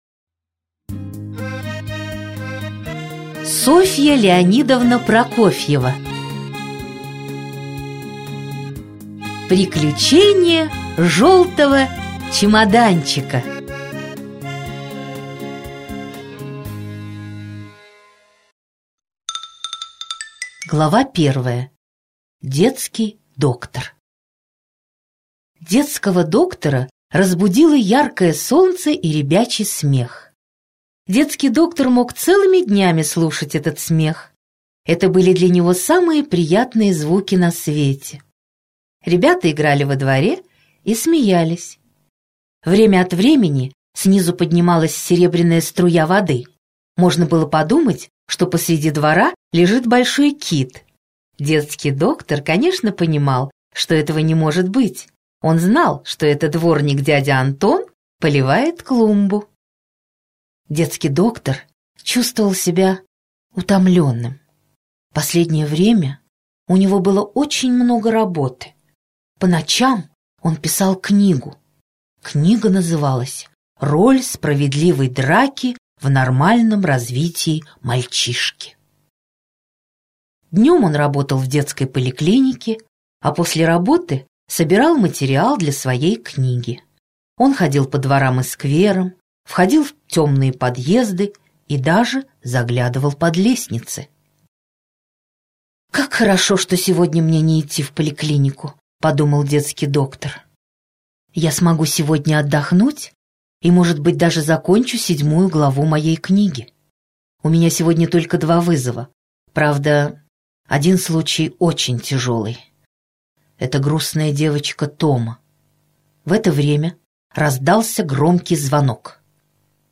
Аудиокнига Приключения жёлтого чемоданчика - купить, скачать и слушать онлайн | КнигоПоиск